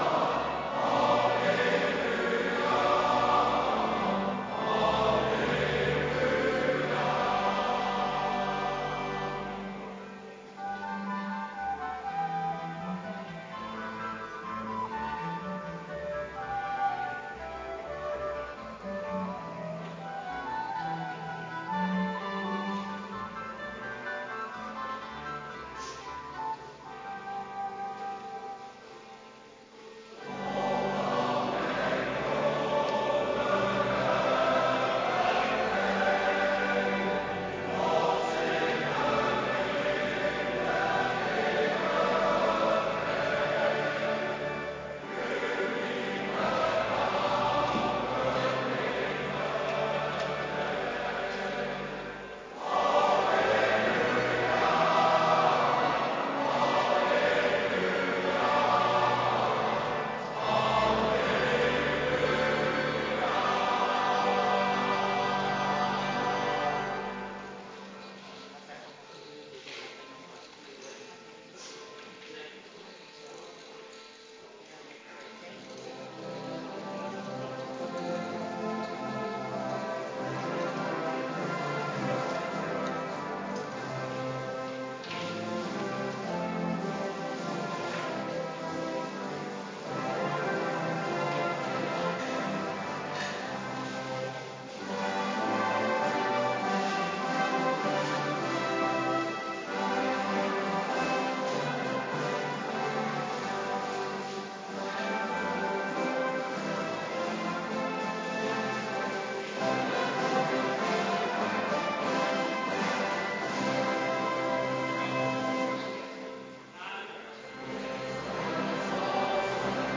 Kerkdiensten
Adventkerk Zondag week 3